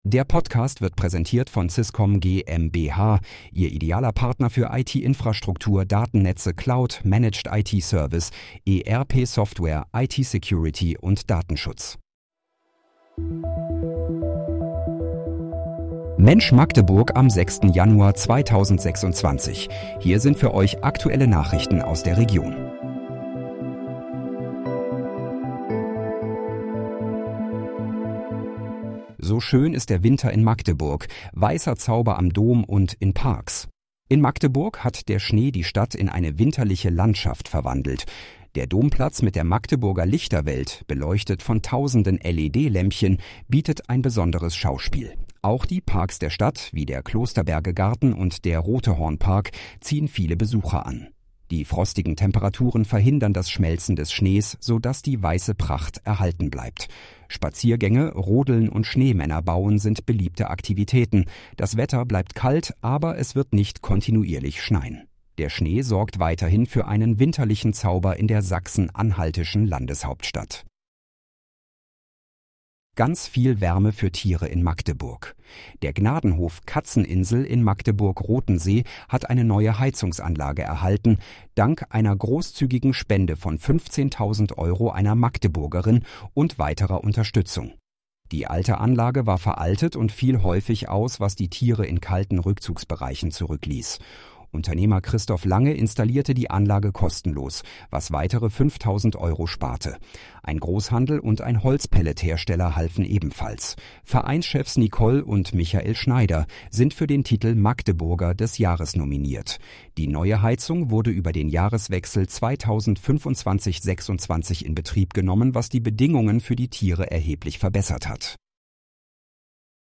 Mensch, Magdeburg: Aktuelle Nachrichten vom 06.01.2026, erstellt mit KI-Unterstützung